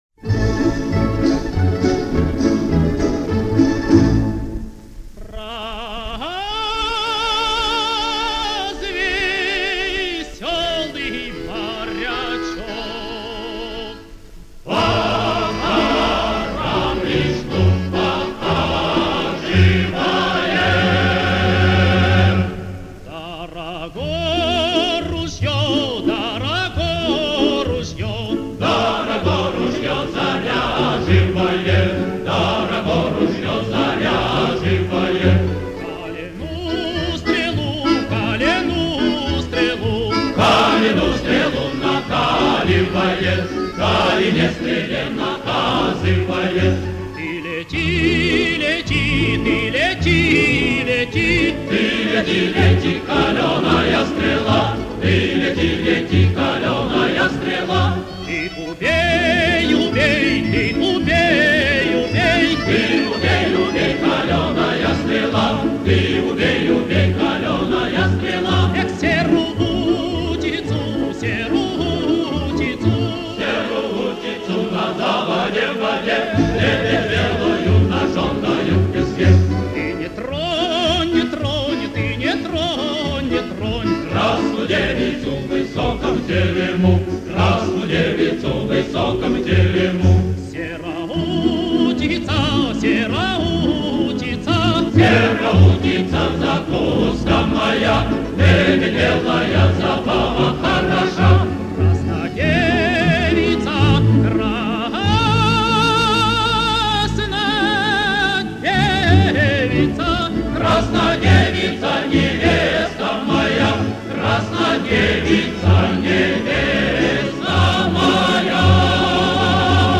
Певческий стиль близок к "александровцам".
Музыка: русская народная песня, обр.